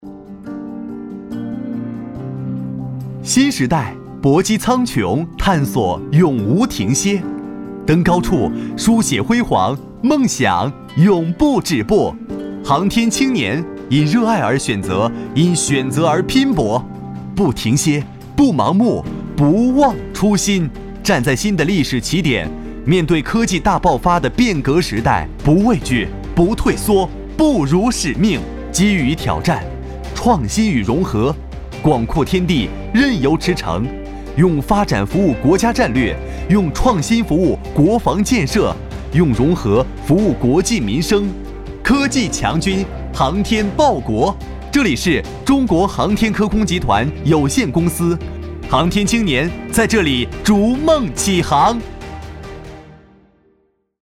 男国350年轻时尚配音-新声库配音网
男国350_专题_人物_航天青年_年轻.mp3